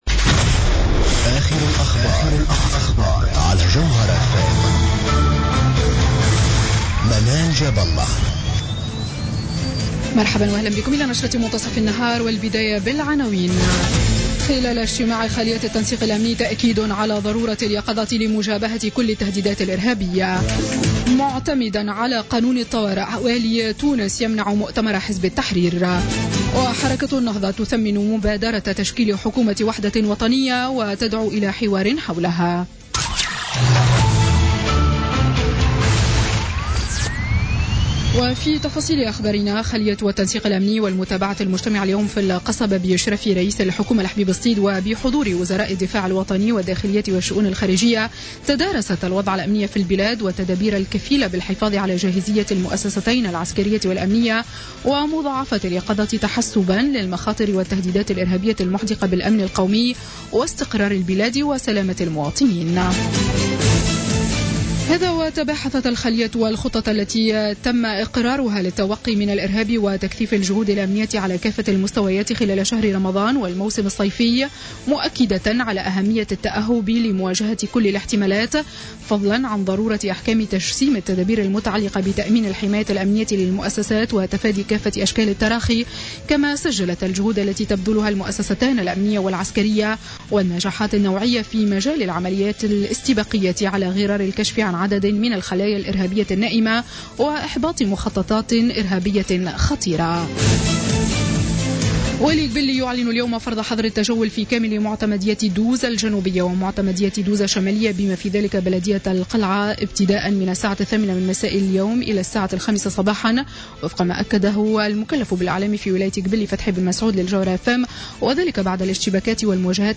نشرة أخبار منتصف النهار ليوم السبت 4 جوان 2016